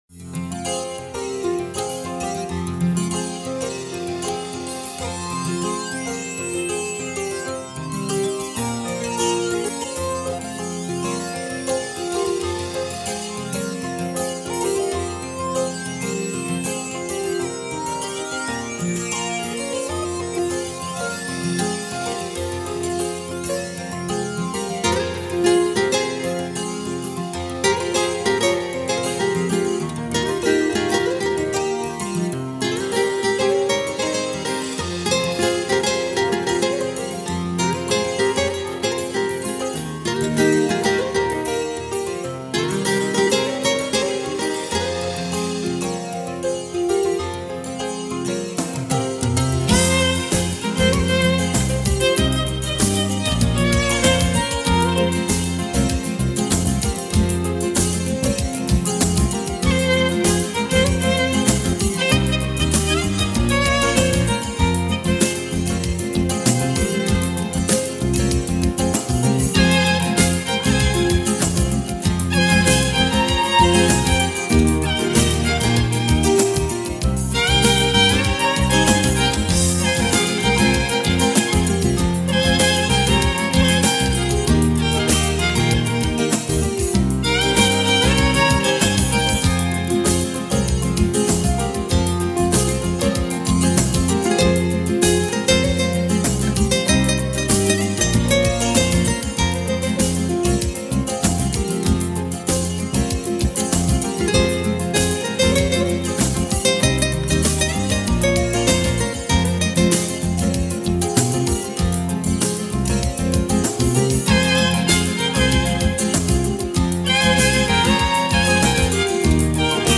در سبک پاپ
ویلن (سلو)/گیتار
پیانو/کیبورد
گیتار الکتریک
ضبط: استودیو پاپ
(بی کلام) - موسیقی یونانی